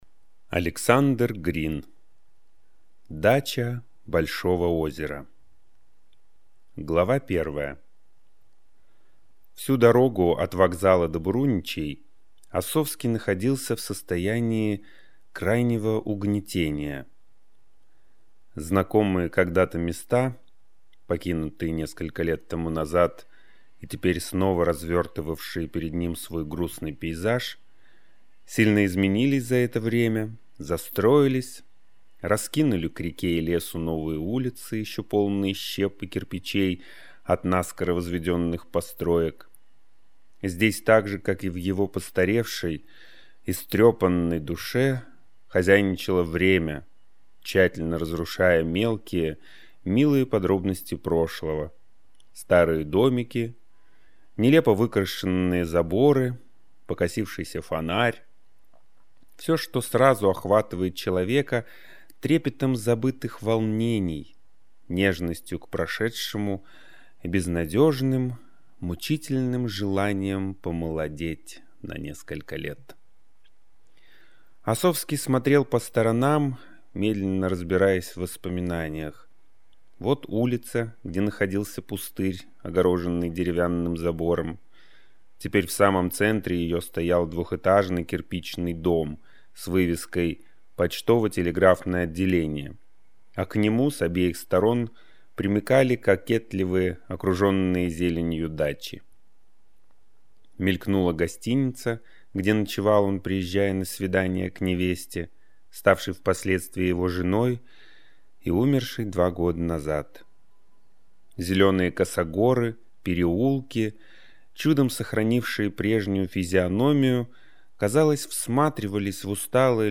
Аудиокнига Дача Большого озера | Библиотека аудиокниг